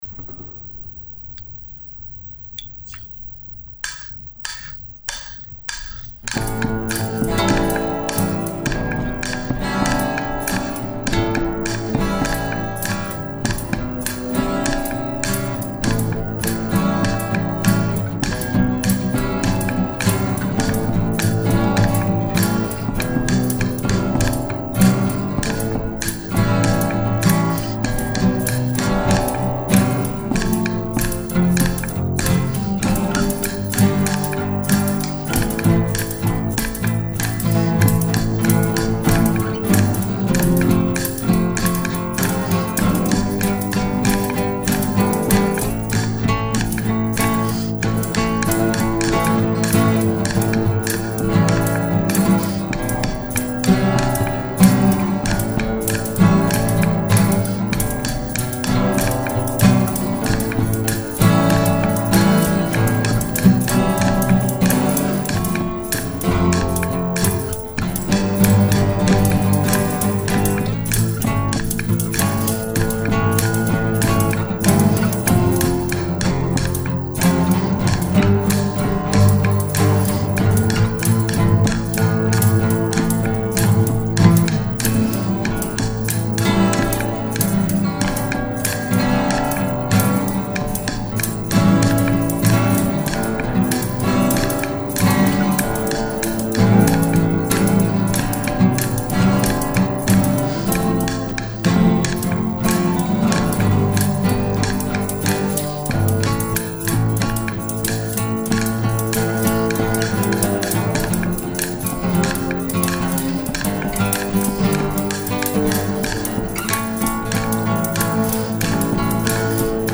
sweet-instrumental_mixdown.mp3